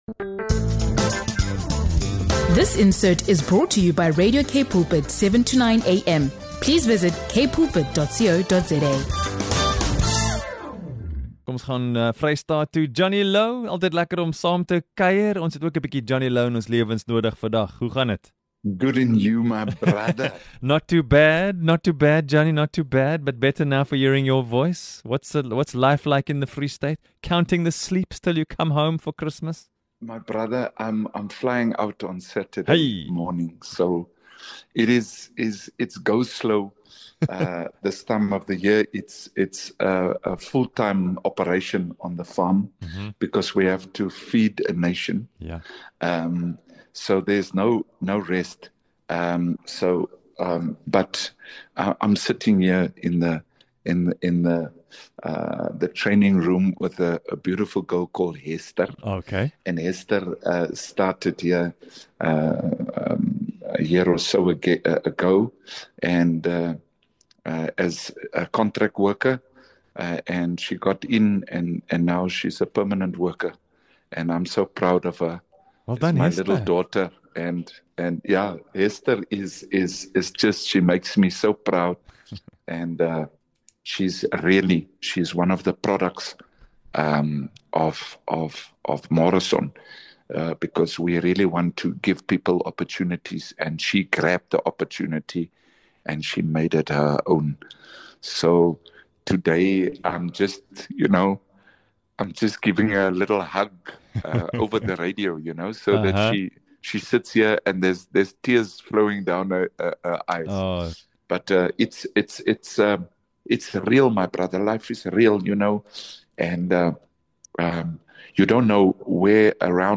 This heartfelt discussion offers a practical, transformative perspective on the Fruit of the Spirit (Galatians 5:22-23). The key insight is to read the list backwards: start by actively practising self-control, gentleness, and faithfulness in your own life. Then, extend those cultivated qualities to others through goodness, kindness, and patience.